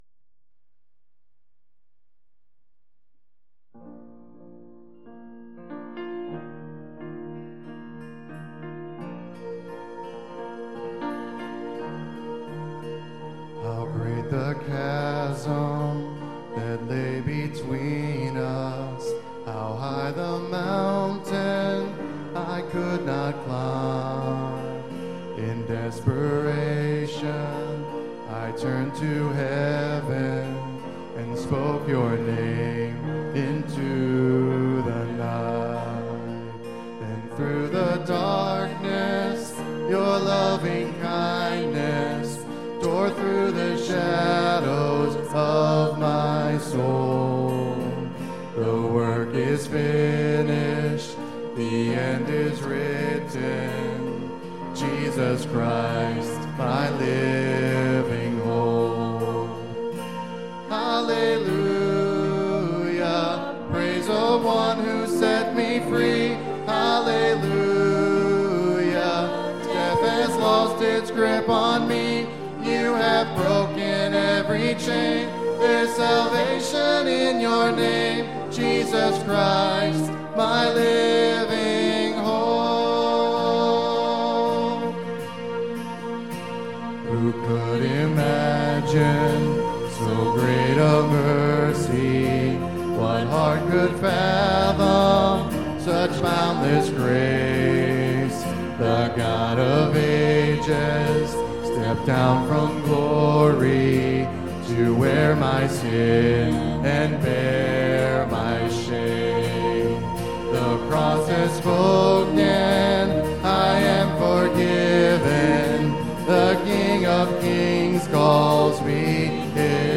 Philippians 4:11-13 Service Type: Sunday AM Bible Text